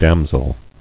[dam·sel]